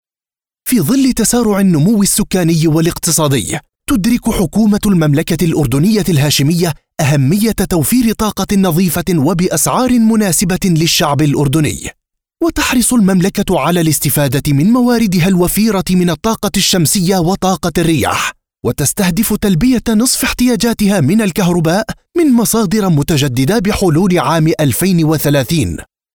I record In MSA Arabic (Fusha) and Jordanian or Palestinian accent.
Video Games and Cartoons